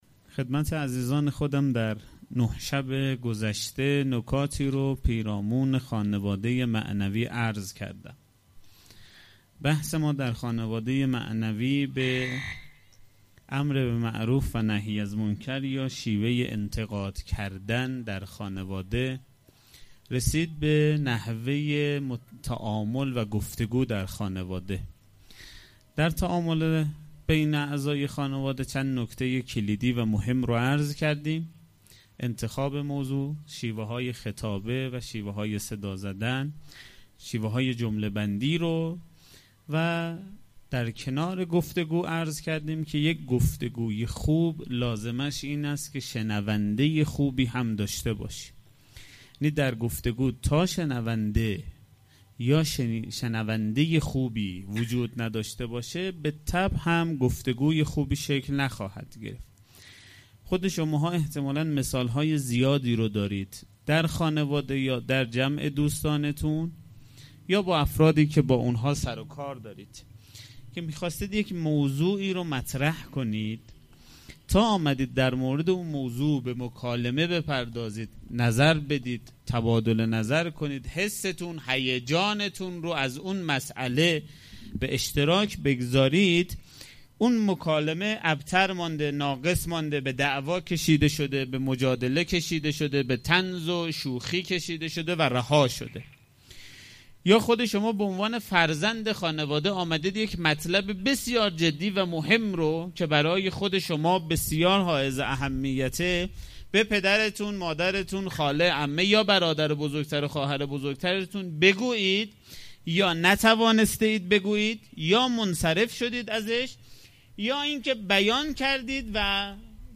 سخنرانی شب دهم